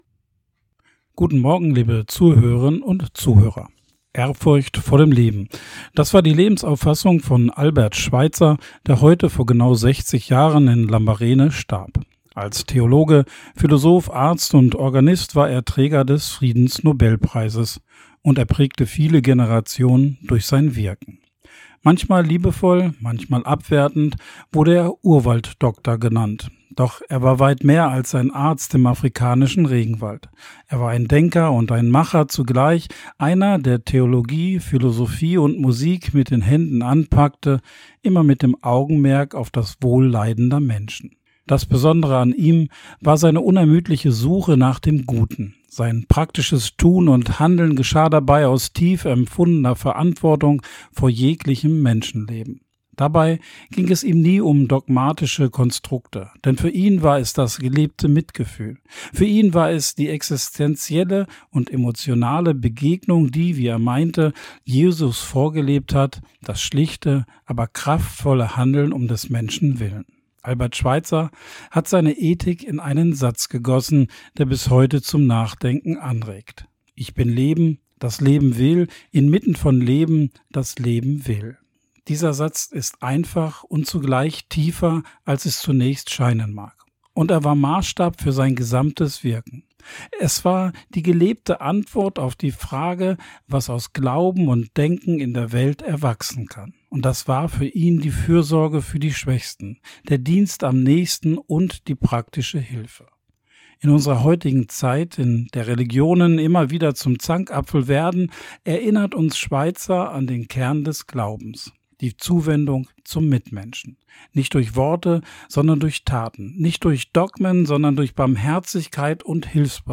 Radioandacht vom 4. September